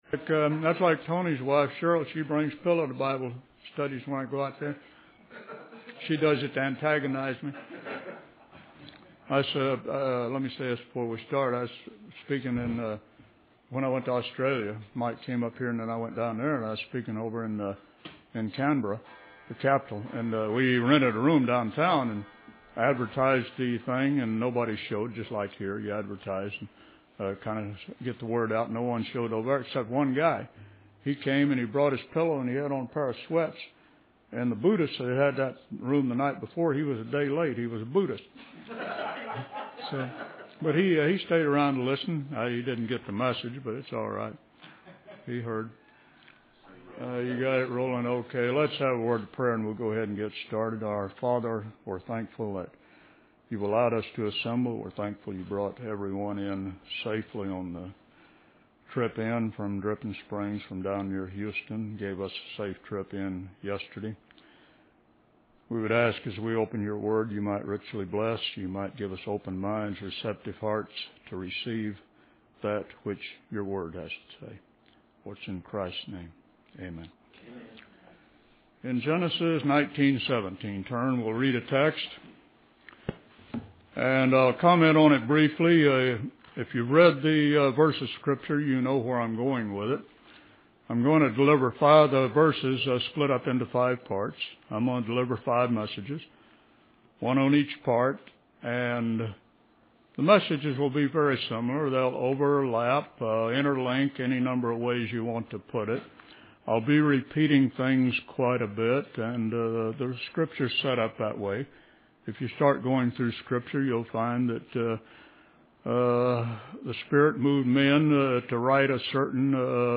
In this sermon, the speaker begins by praying for God's blessings and guidance as they open the Word of God.